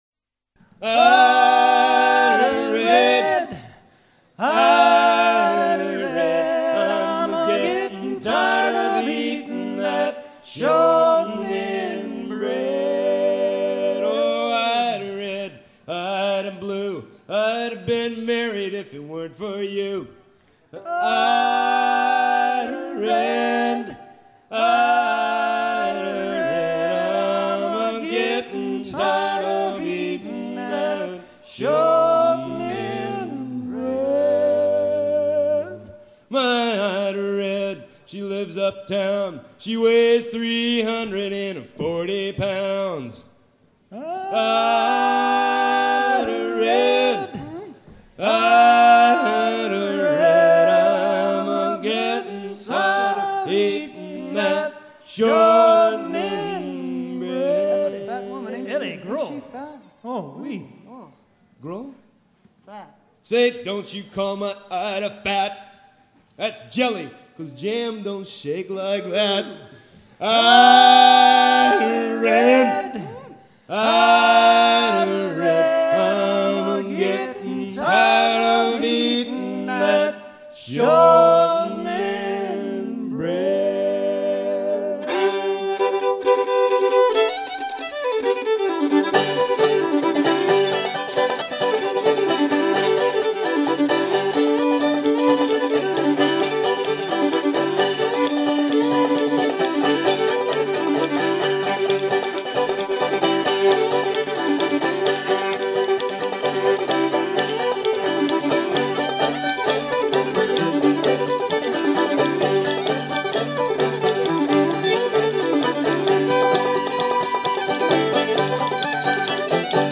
chant rythmant le travail d'arrimage dans la cale d'un navire